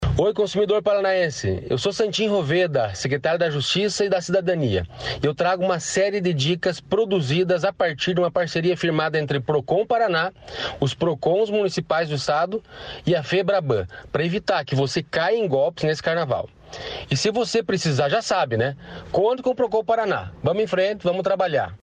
Sonora do secretário da Justiça e Cidadania, Santin Roveda, sobre a divulgação de dicas para o consumidor no Carnaval